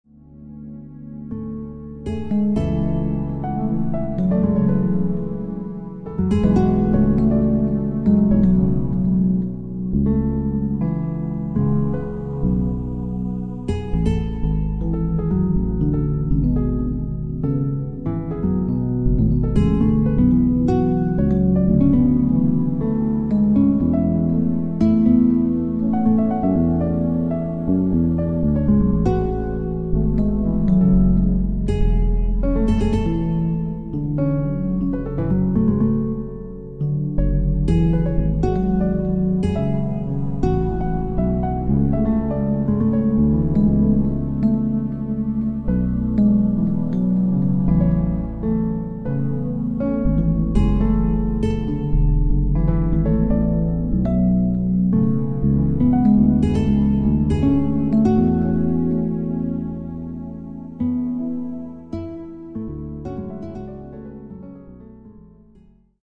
Die so gewonnenen Daten werden nach bestimmten Rechenkriterien in sogenannte "MIDI-Befehle" gewandelt, die nun einen oder mehrere angeschlossene elektronische Tonerzeuger zum Klingen bringen. Am Ende kommt so eine sehr spezielle "Musik" dabei heraus.
EEG-Musik.MP3